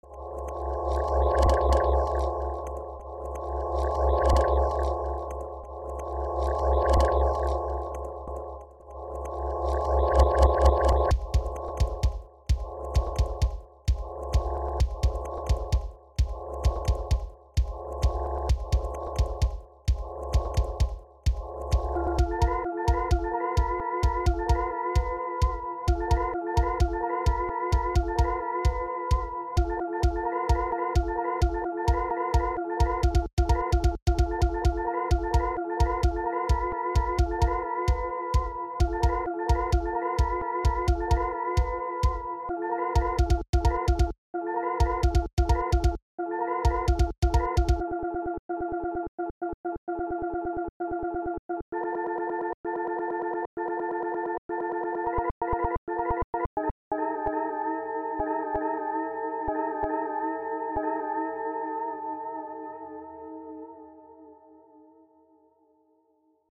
weird, experimental, glitch,